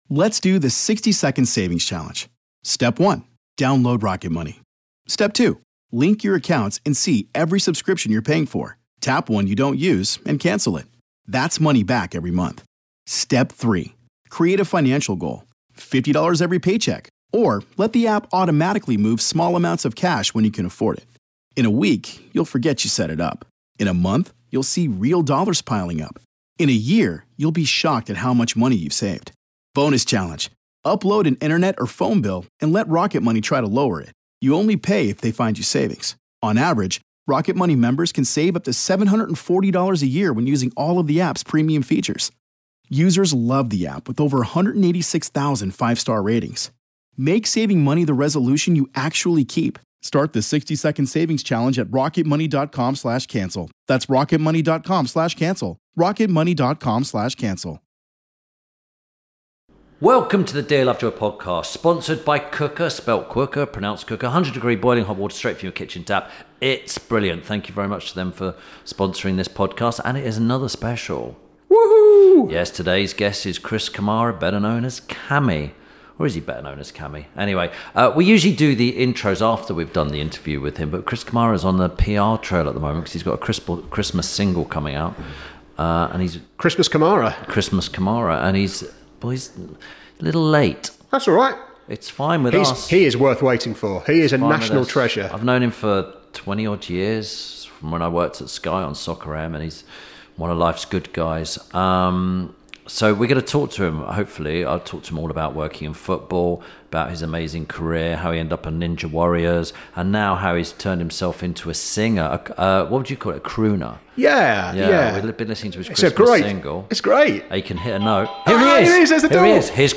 Ep. 151 - CHRIS KAMARA - A Conversation With… – INTERVIEW SPECIAL
– INTERVIEW SPECIAL This week Tim Lovejoy talks to football presenter, pundit and now Christmas crooner Chris Kamara. Tim and Chris discuss his new Christmas album Here’s To Christmas, working together on Soccer AM, his punditry technique and how he started his football career in the navy.